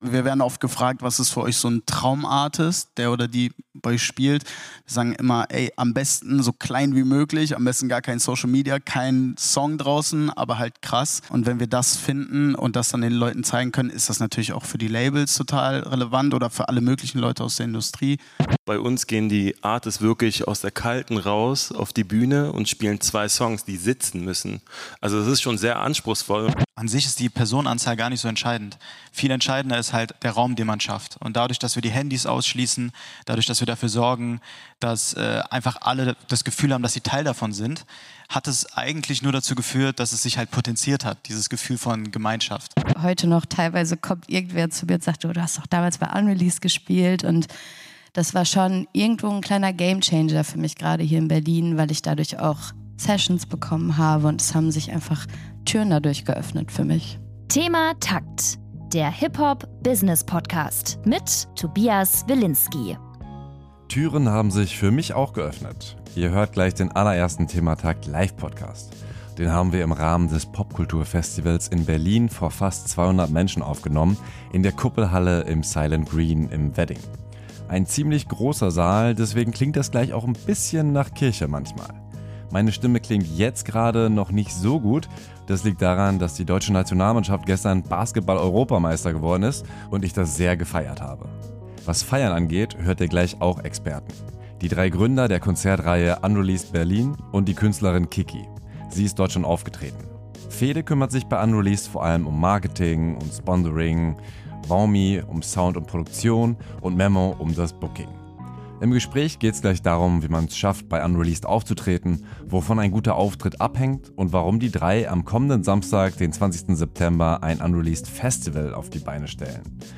Im ThemaTakt-Live-Podcast auf dem Pop-Kultur-Festival in der Kuppelhalle im Silent Green